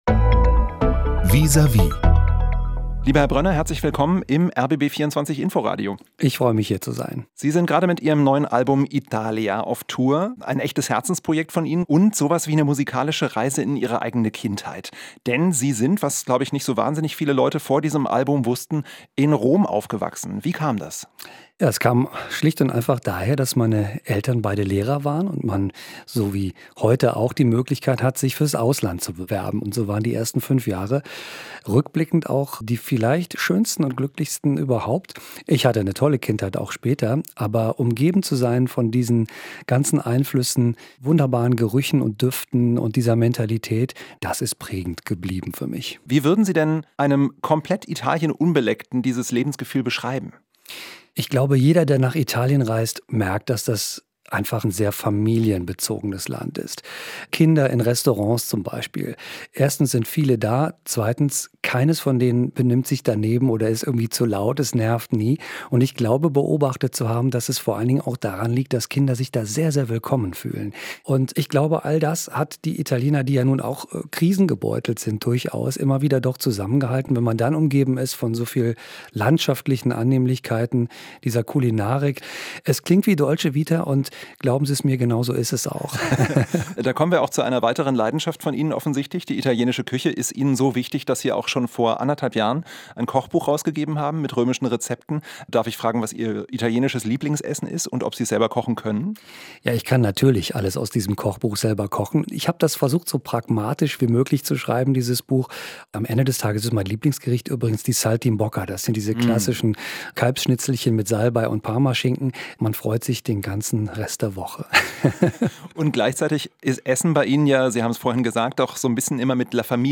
Aktuell ist der Jazz-Trompeter mit seinem Album "Italia" auf Tour. Im Vis à vis spricht er über Italien als Sehnsuchtsort und die mehrfach verschobenen Pläne für ein House of Jazz in Berlin.